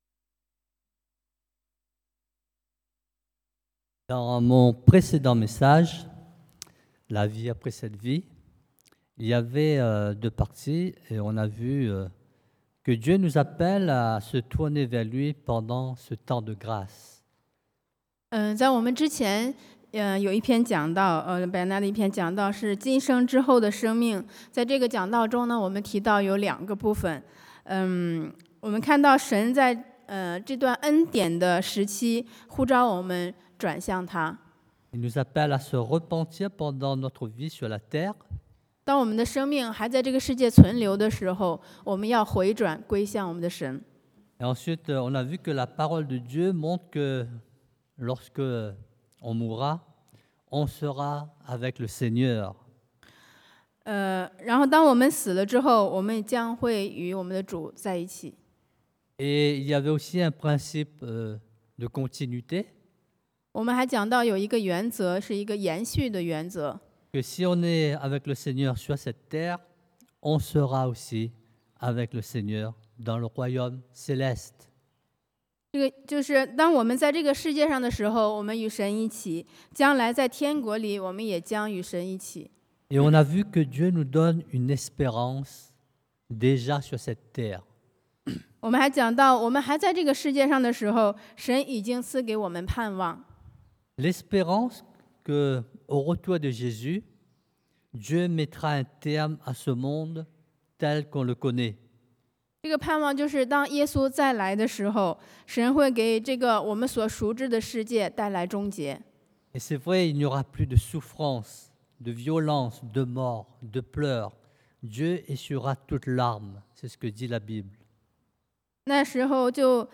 Predication du dimanche